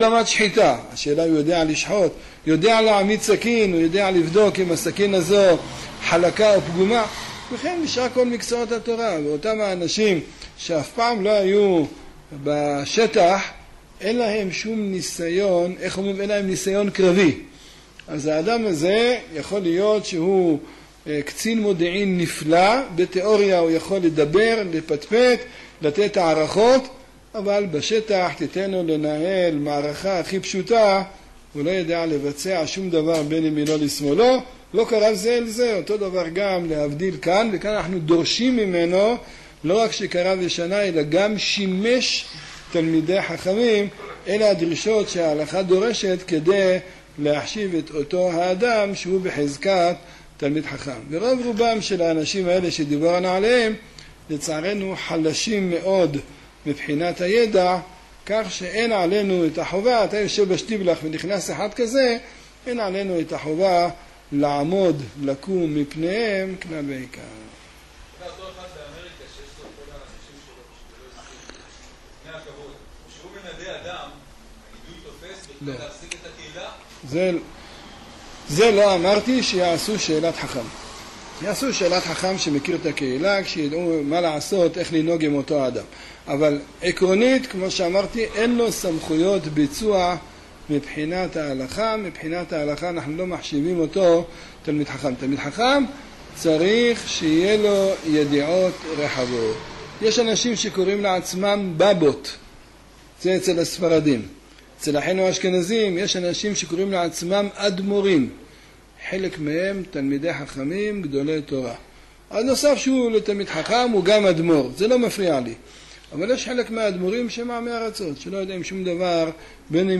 קטע מתוך שיעורו של הרב יעקב יוסף זצ"ל